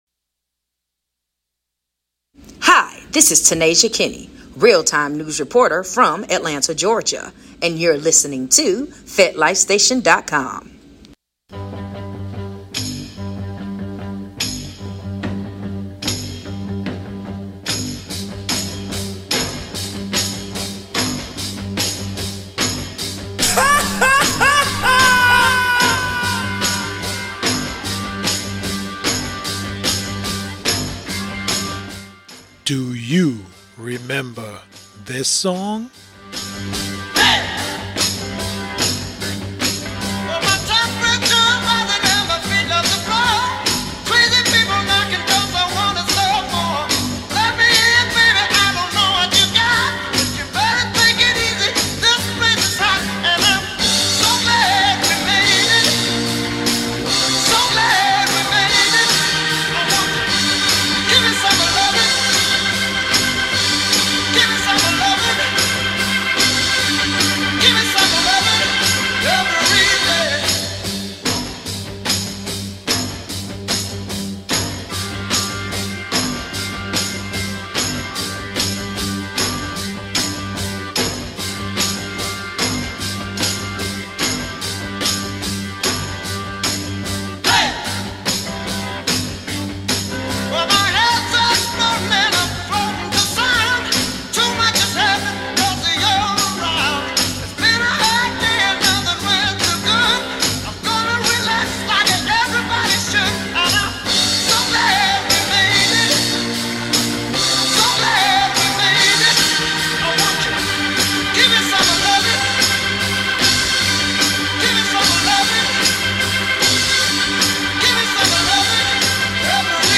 music old school